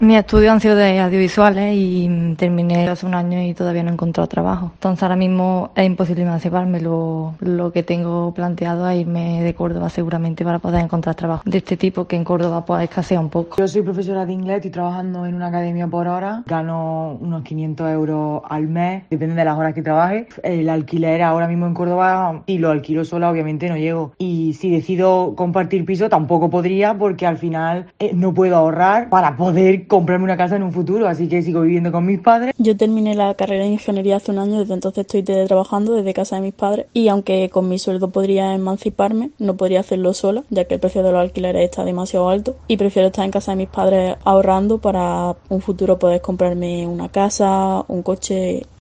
Tren de voces gente joven sobre emancipación